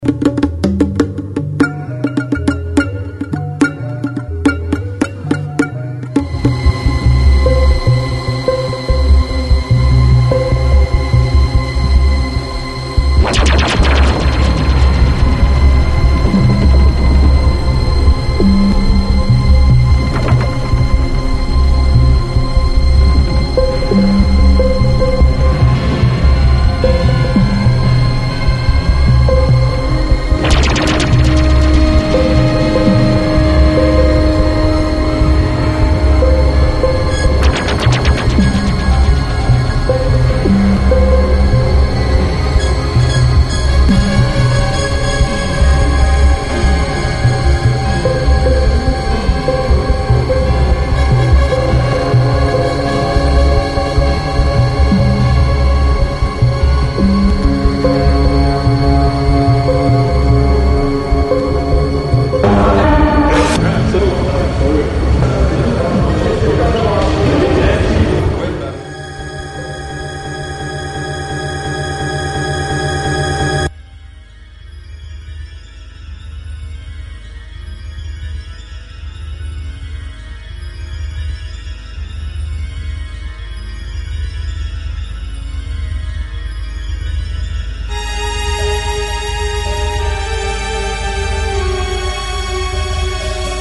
As an electronic symphonic music creation